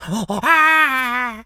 Animal_Impersonations
monkey_chatter_angry_08.wav